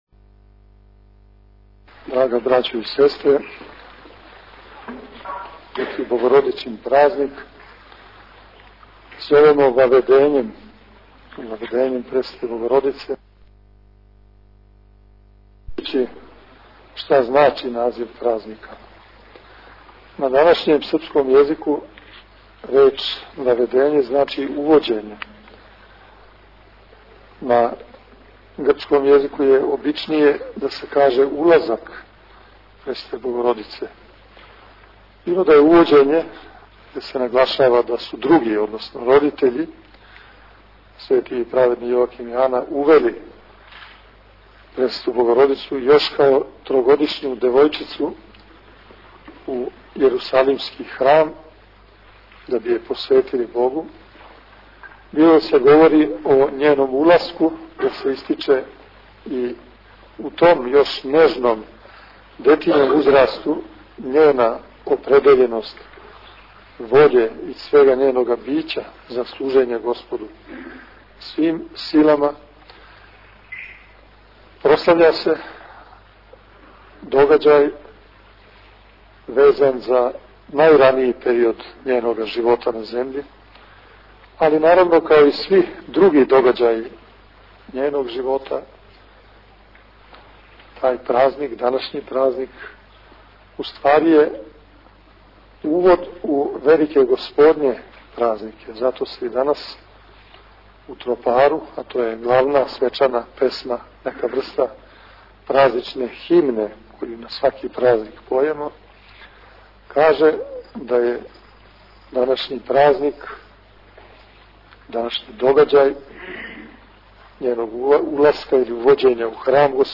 Свечаним евхаристијским сабрањем у манастиру Бођани прослављена је слава манастира Ваведење Пресвете Богородице.
После прочитаног одељка из светог Јеванђеља, присутном народу се беседом обратио Владика Иринеј, поучивши присутни многобројни верни народ о значају и улози Пресвете Богородице и празника Ваведења.